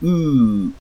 Close_central_rounded_vowel.ogg.mp3